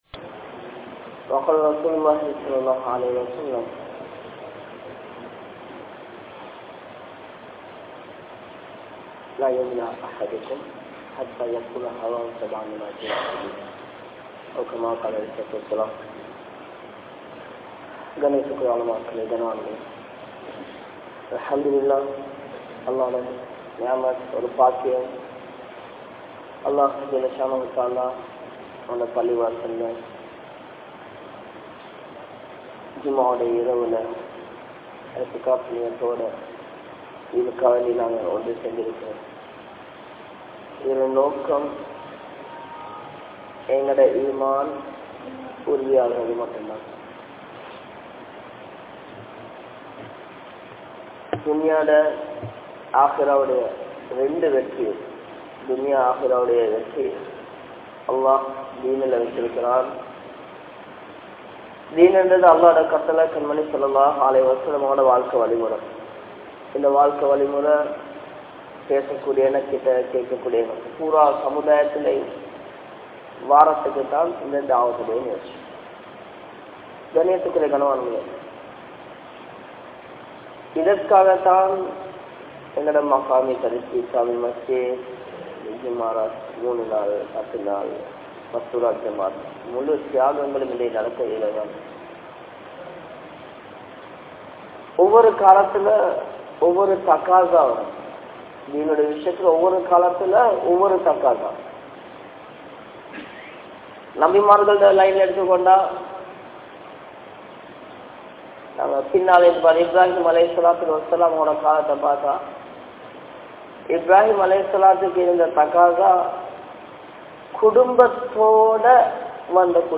Thiyaahaththin Mudivu (தியாகத்தின் முடிவு) | Audio Bayans | All Ceylon Muslim Youth Community | Addalaichenai